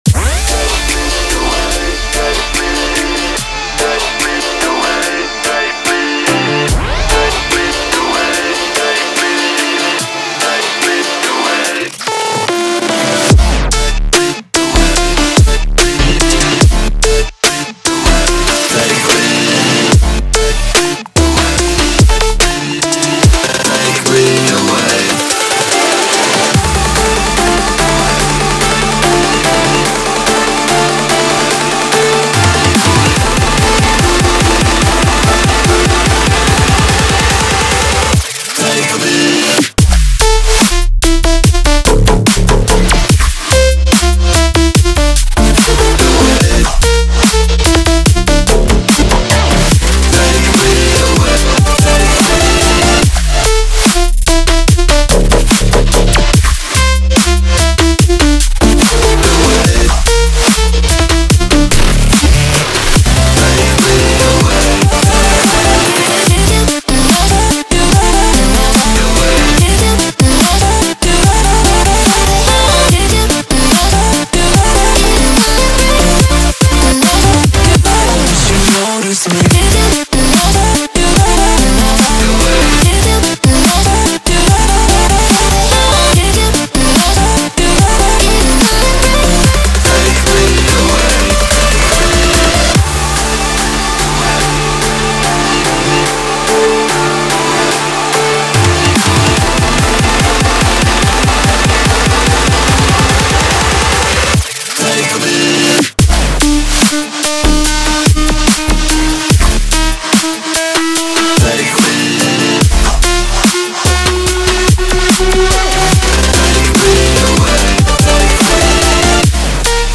• Жанр: Dubstep